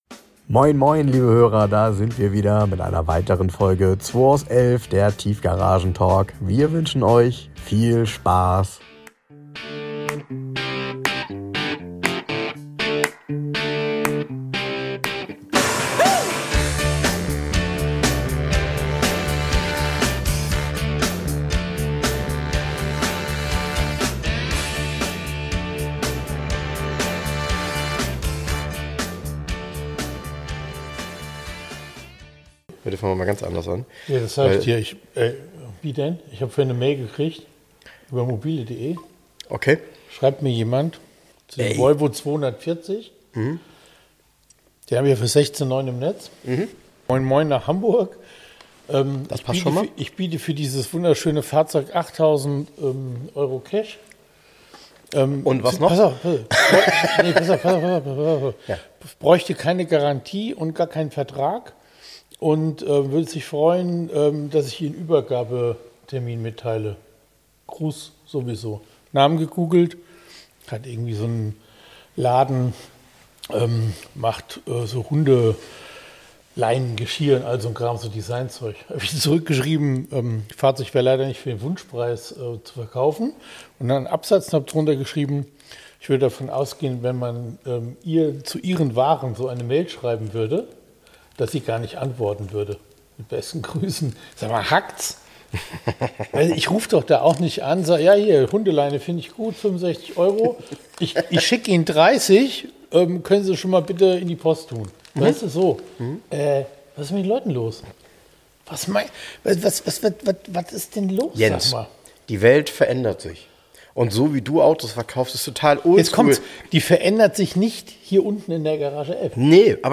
aufgenommen in der Garage 11 in Hamburg. Wir reden über Neuigkeiten aus der Szene und dem Klassiker Markt und interviewen regelmäßig Gäste und therapieren deren automobile Vergangenheit. Wir diskutieren über zukünftige Klassiker und stellen Euch besondere Fahrzeuge und ihre Geschichte vor.